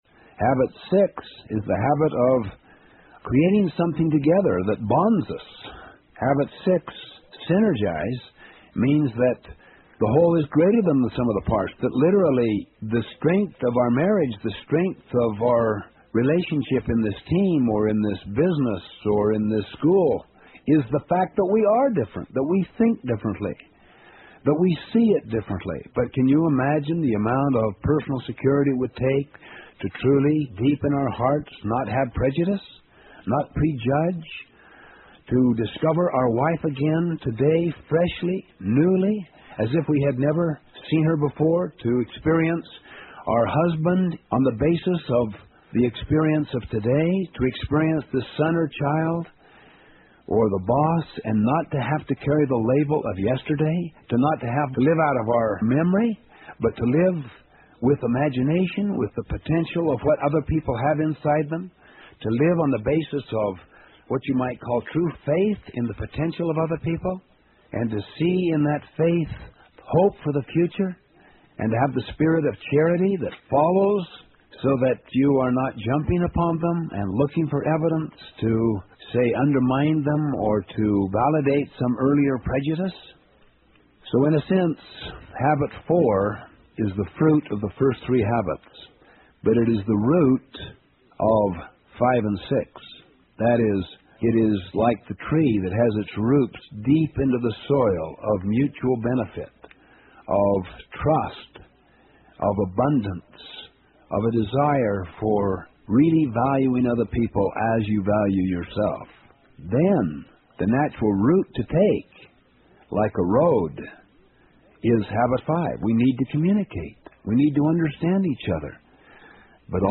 有声畅销书：与成功有约07 听力文件下载—在线英语听力室